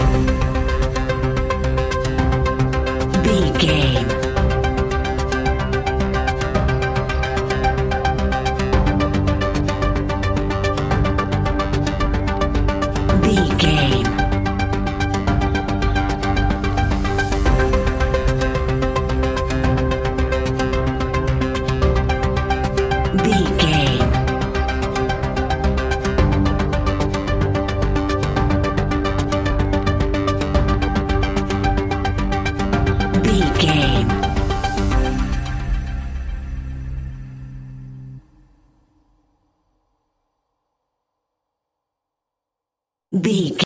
In-crescendo
Thriller
Aeolian/Minor
ominous
dark
dramatic
eerie
instrumentals
horror music
Horror Pads
horror piano
Horror Synths